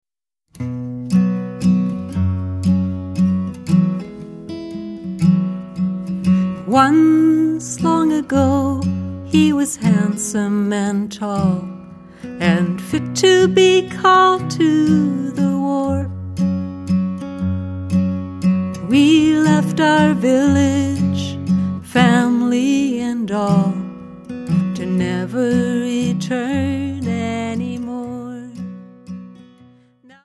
Fiddle and harmonies